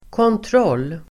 Uttal: [kåntr'ål:]